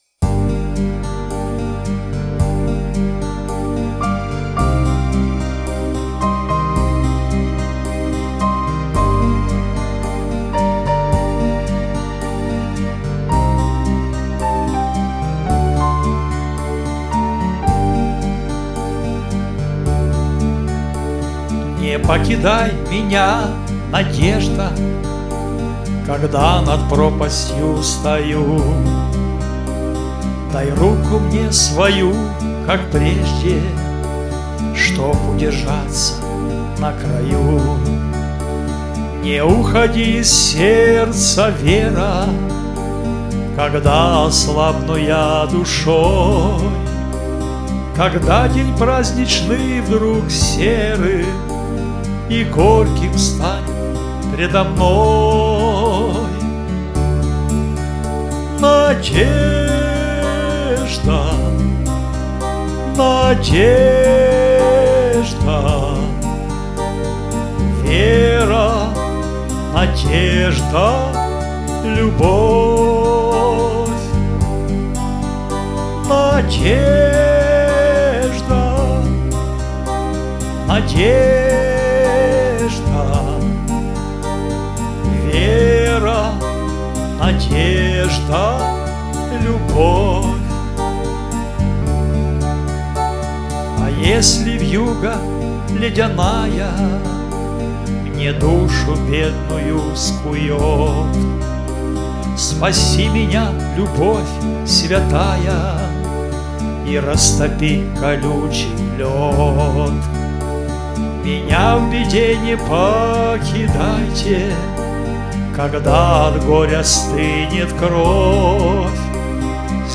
Главная » Файлы » Авторские песни.
Христианские песни